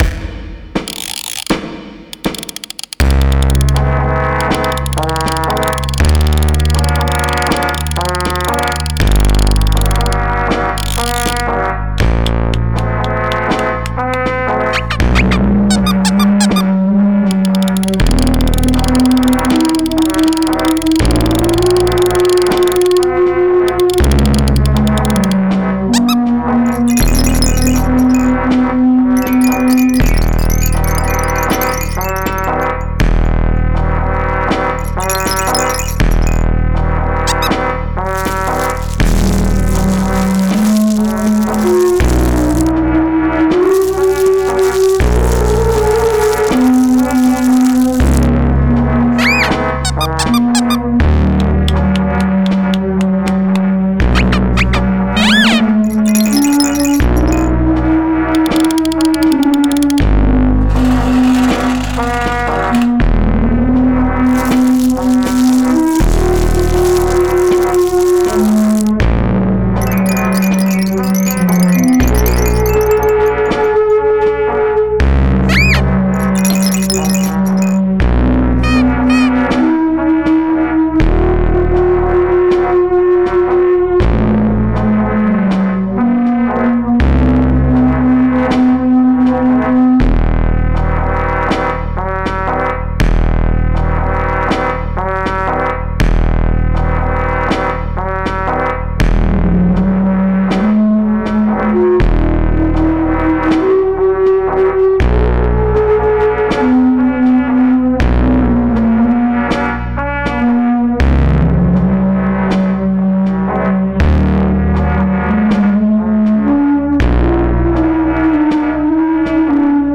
256SOUND consists out of a visual and AI generated auditory artwork, derived from the data in the genesis 256 ART pieces.